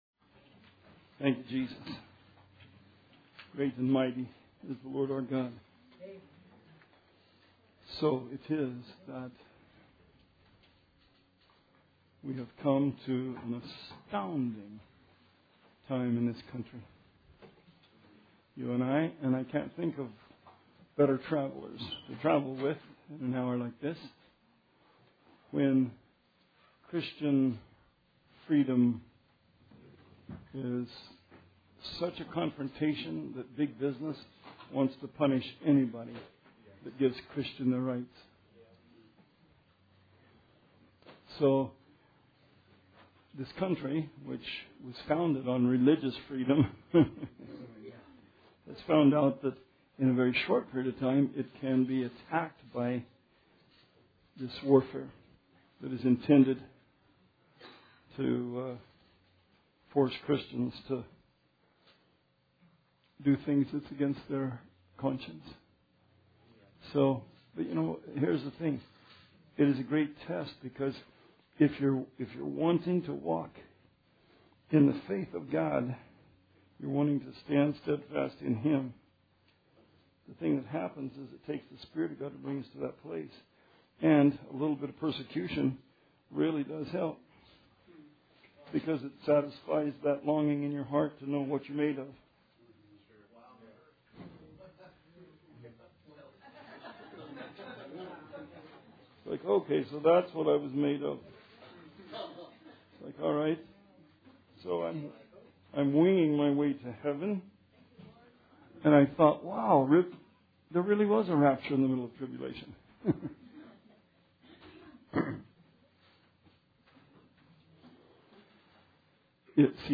Bible Study 4/13/16